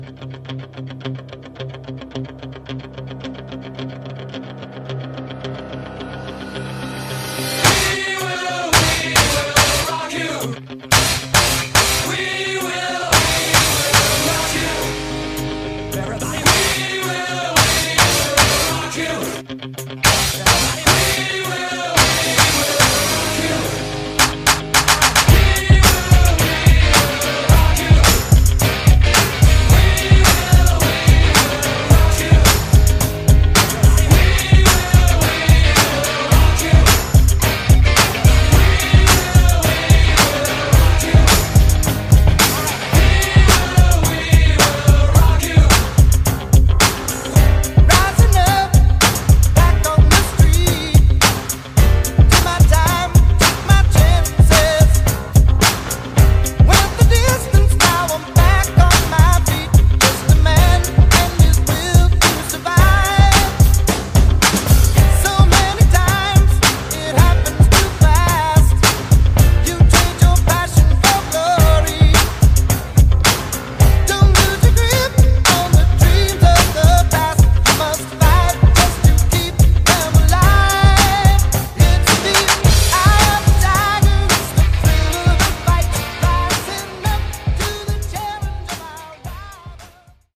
80’s Re-Drum